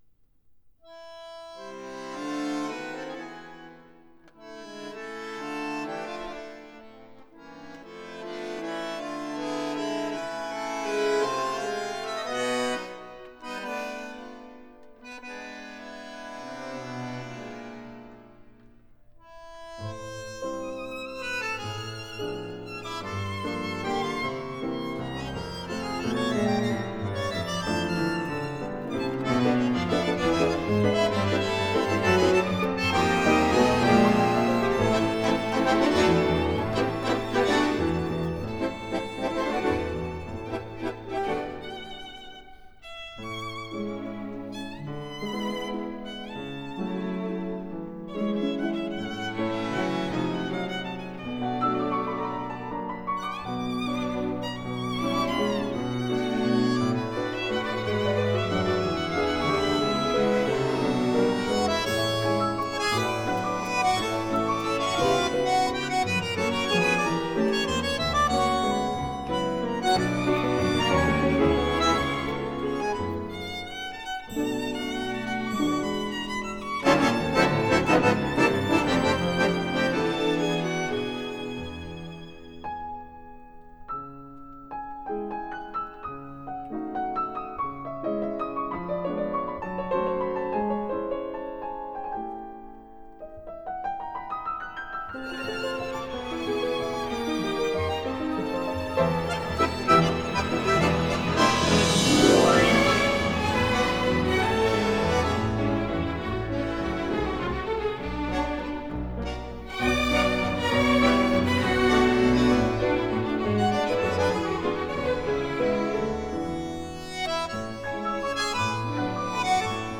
Genre: Tango
Recorded at MCO Hilversum, The Netherlands, April 2002.